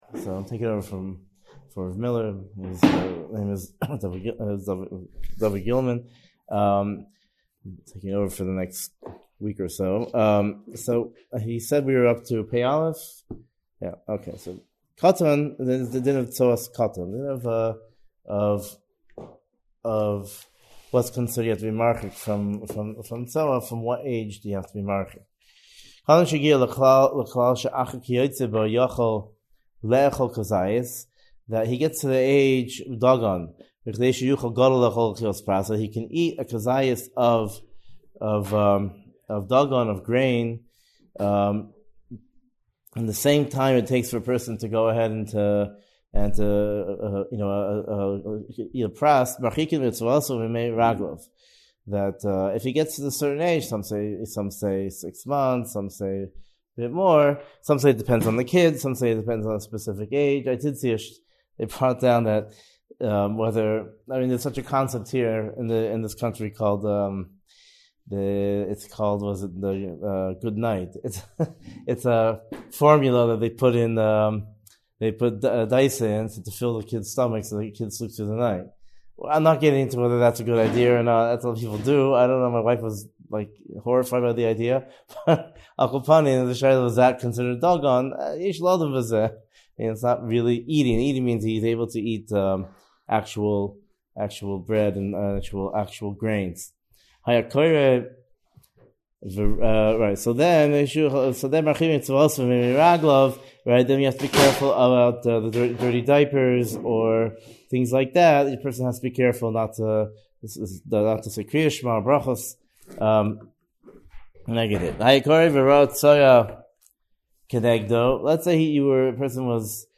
Shiur